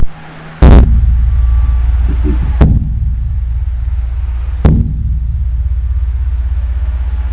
The drum used to summon the faithful on days of prayer (water buffalo hide)
Vat Khi Li, Luang Prabang, Laos